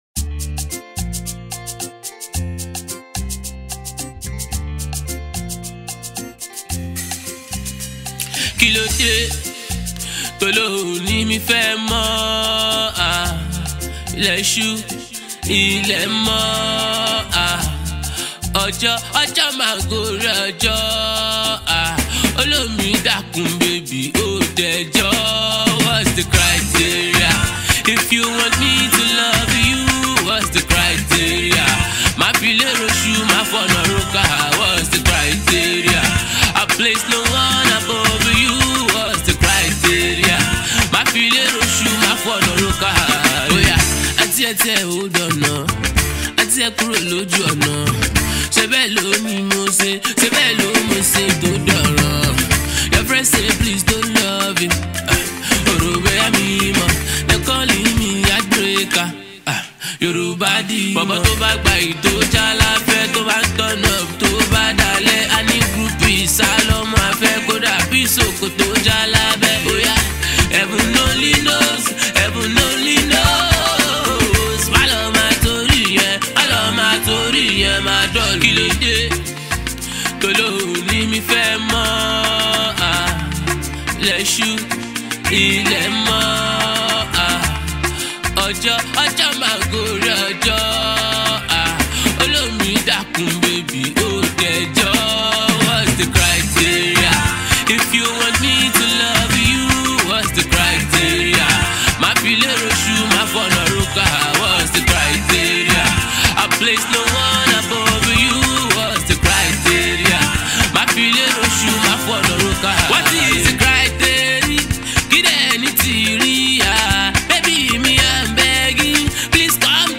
low-tempo love song